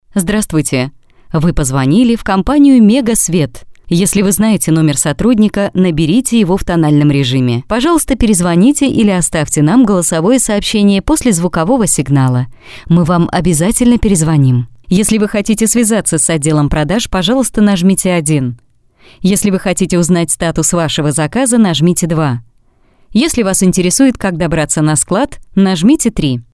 Тракт: Rode nt1 Tube Pac M-audio
Демо-запись №1 Скачать